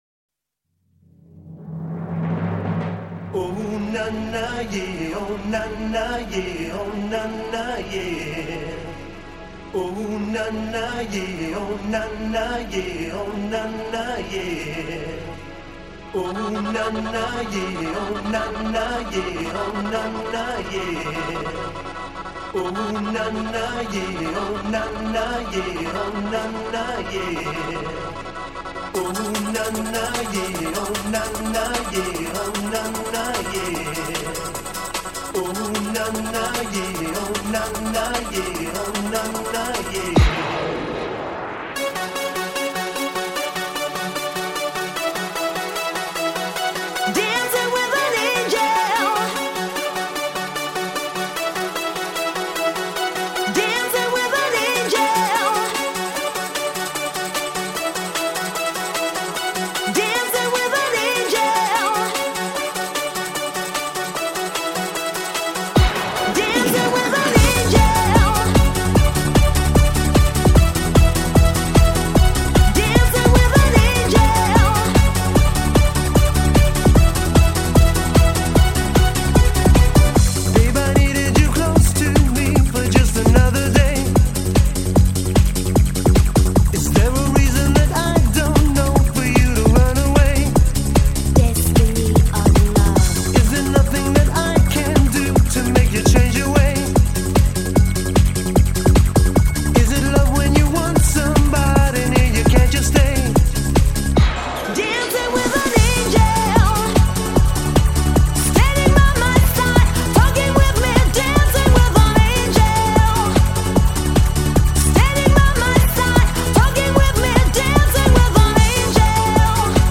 Жанр: Eurodance